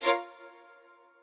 strings11_9.ogg